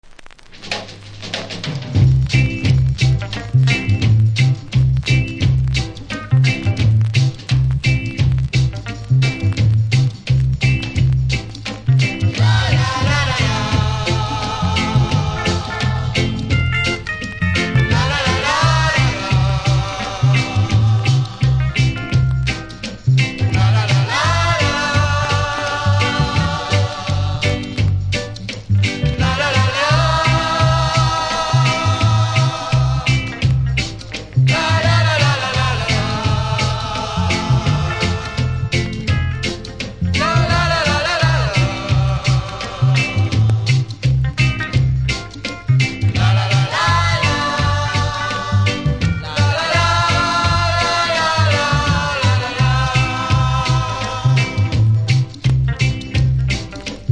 盤に歪みありますがプレイは問題無いレベル。